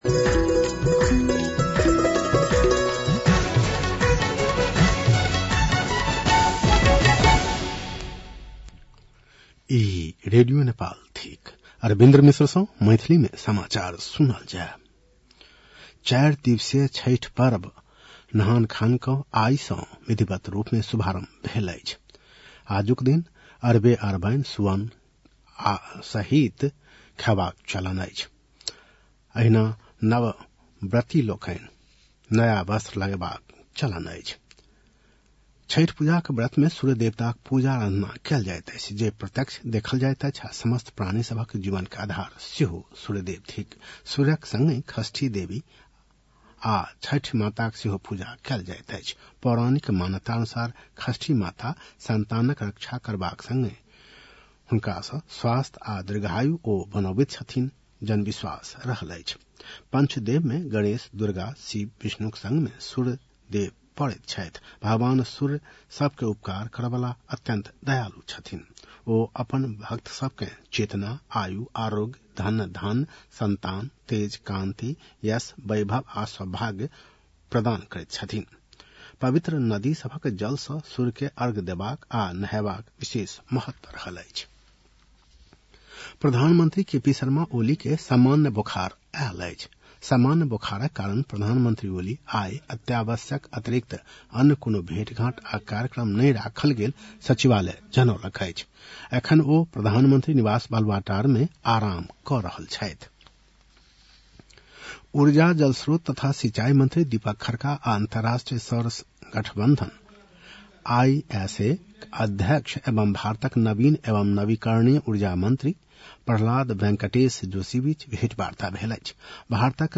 मैथिली भाषामा समाचार : २१ कार्तिक , २०८१